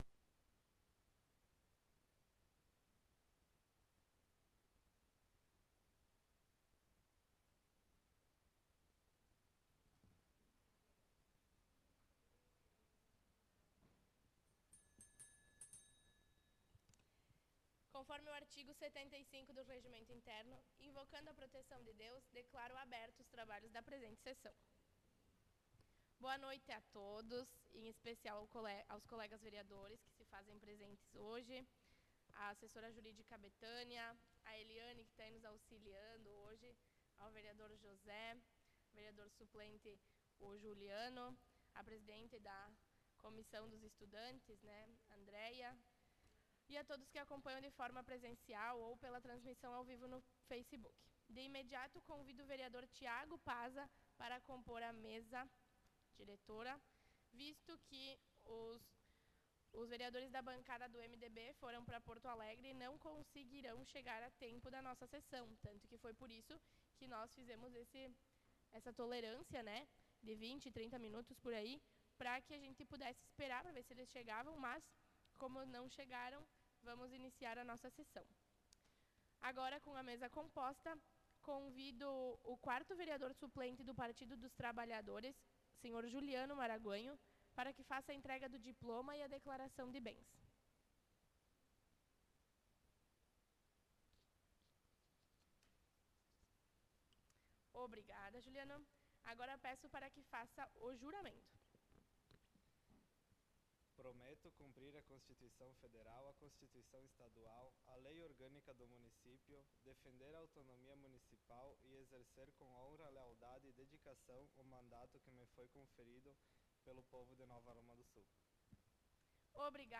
Sessão Ordinária do dia 05/04/2023